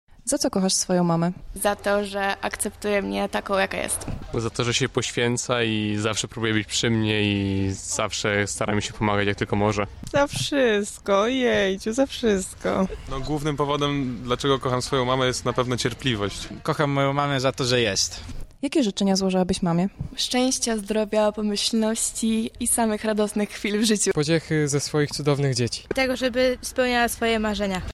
Zapytaliśmy o to mieszkańców Lublina: